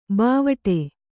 ♪ bāvaṭe